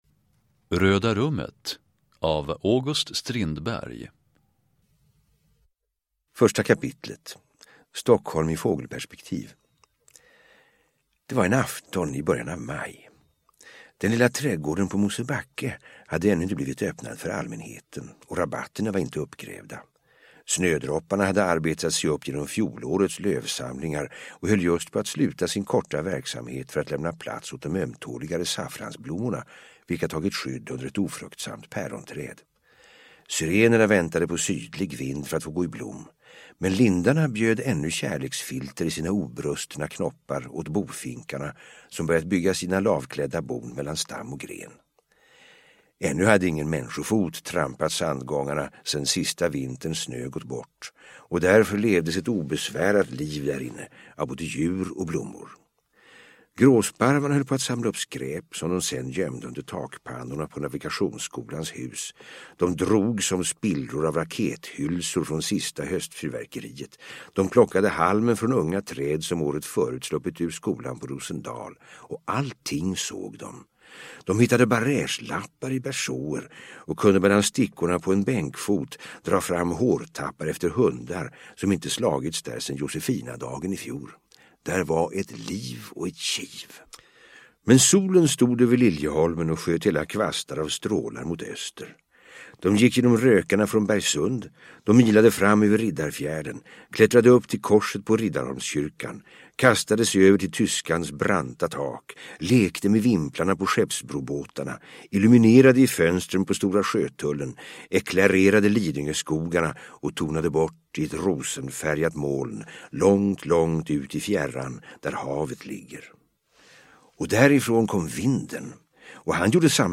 Uppläsare: Per Myrberg
Ljudbok